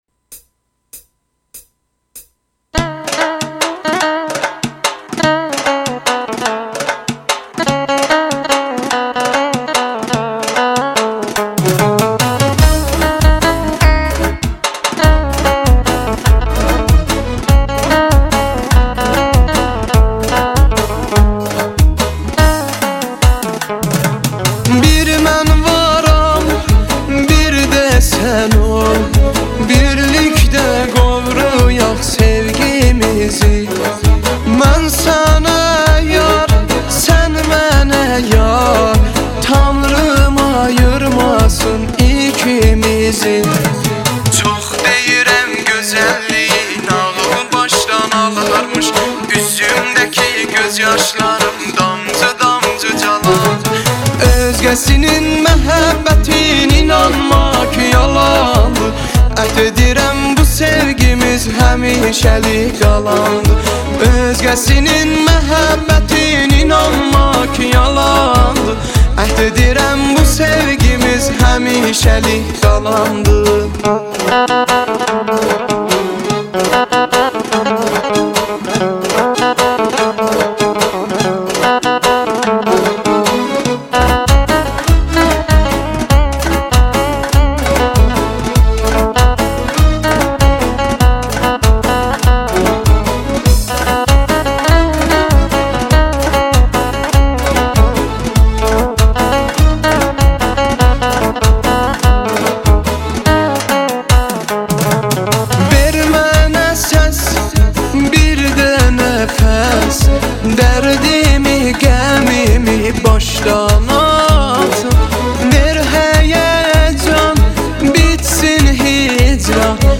آهنگ ترکی و آذری